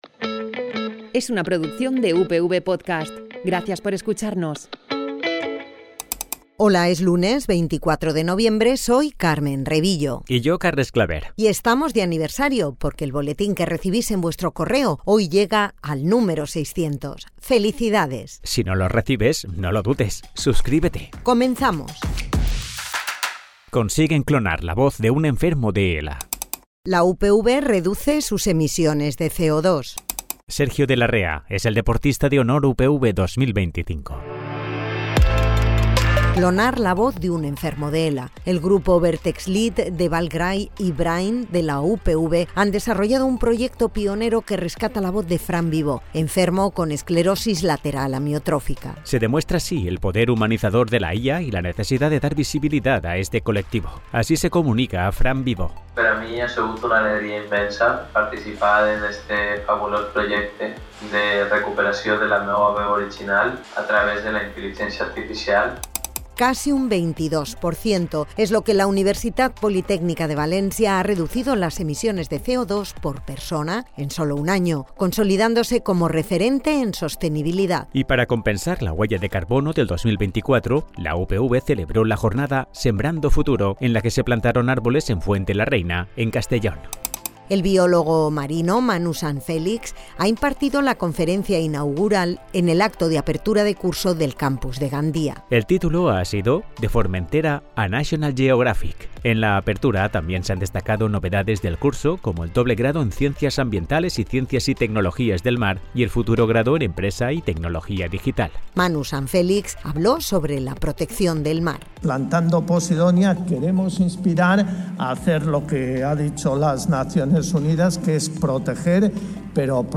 Es la versión sonora del Boletín Informativo para informarte de lo que pasa en la Universitat Politècnica de València.